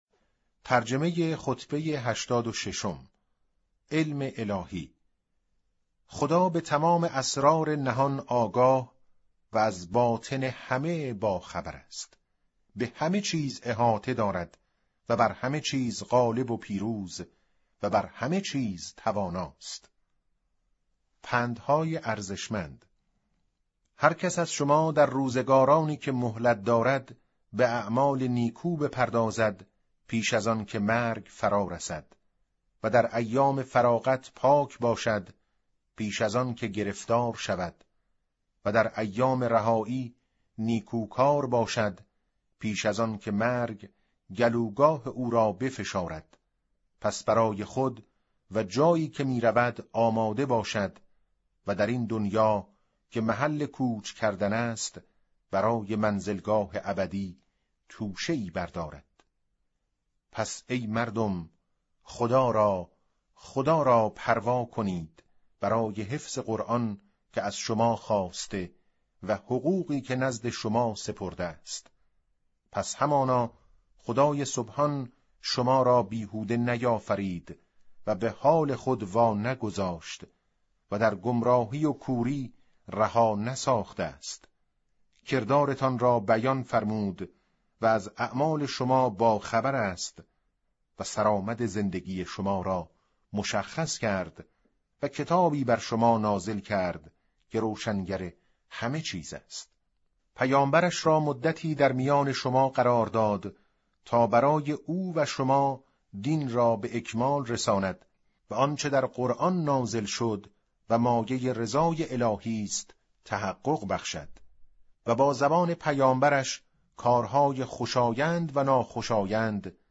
به گزارش وب گردی خبرگزاری صداوسیما؛ در این مطلب وب گردی قصد داریم، خطبه شماره ۸۶ از کتاب ارزشمند نهج البلاغه با ترجمه محمد دشتی را مرور نماییم، ضمنا صوت خوانش خطبه و ترجمه آن ضمیمه شده است: